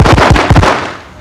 9clapn.wav